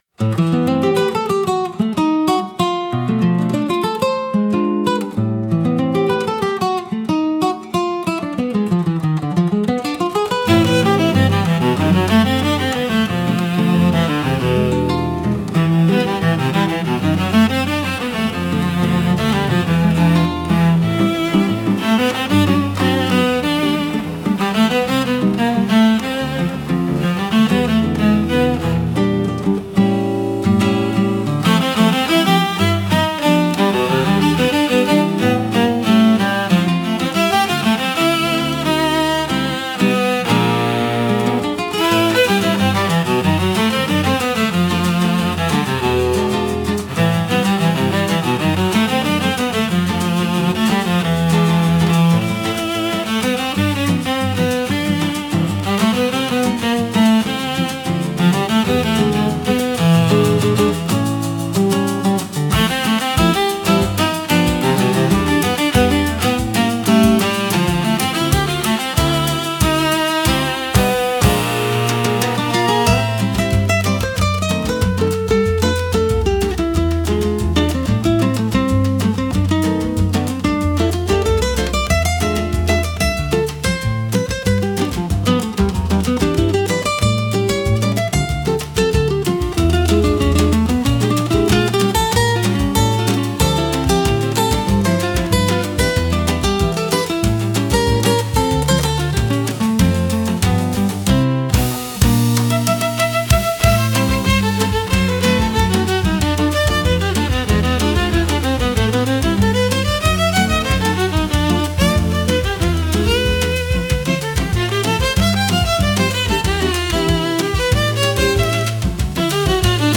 instrumental 4